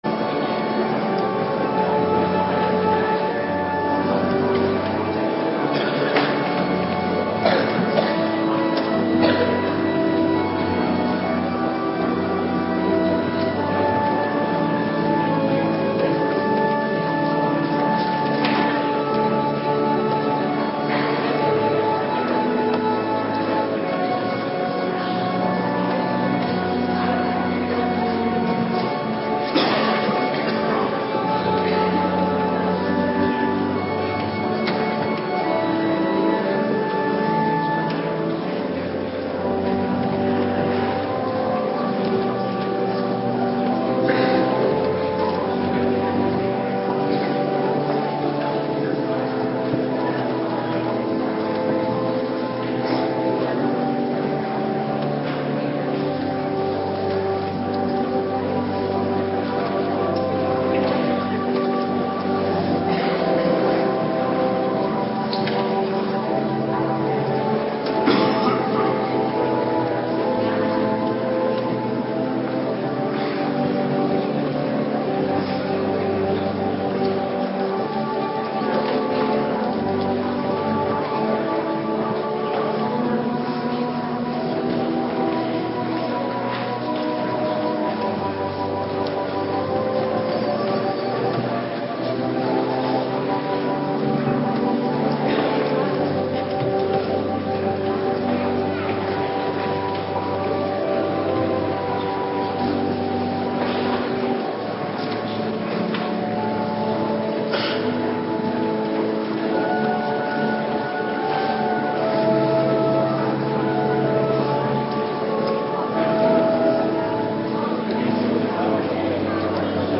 Morgendienst Bed H Doop